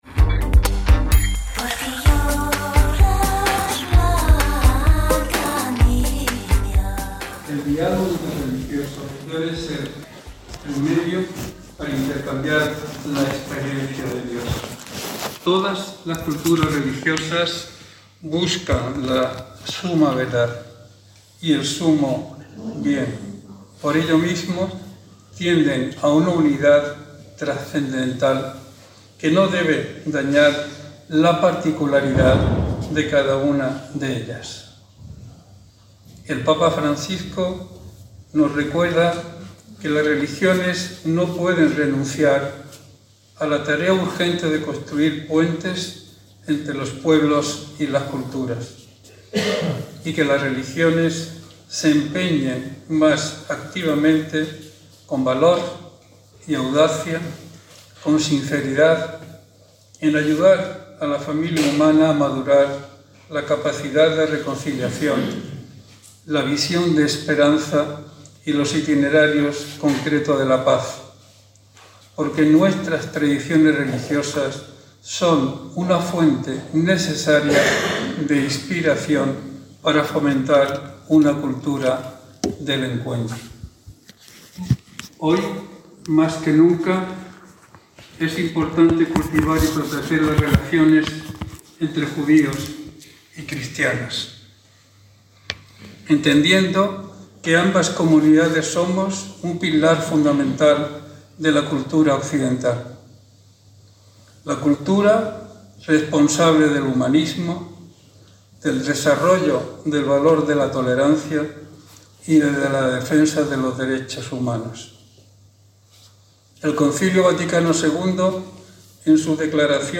Con ocasión de la II Jornada de Amistad Judeo-Cristiana celebrada en Toledo el pasado 7 de abril de 2024
breve discurso de despedida